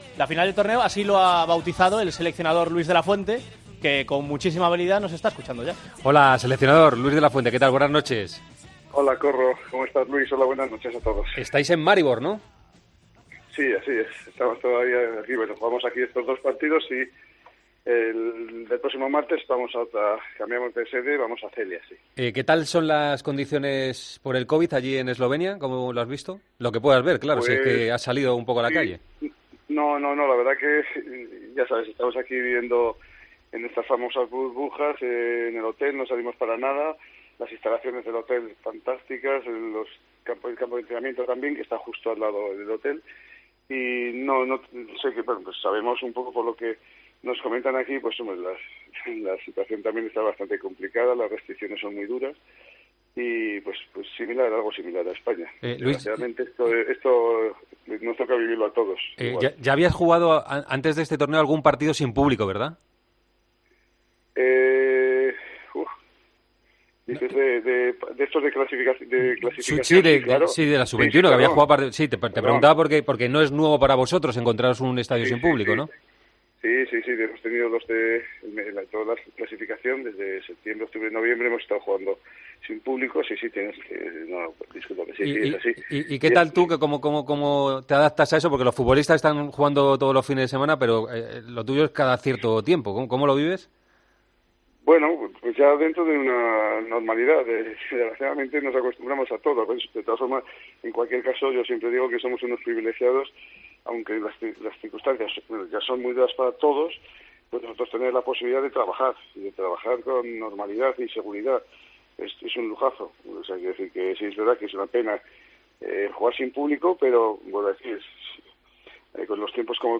Luis De la Fuente, seleccionador español sub-21 ha pasado por El Partidazo de COPE en la previa del segundo partido de La Rojita en el Europeo tras ganar a Eslovenia por 0-3.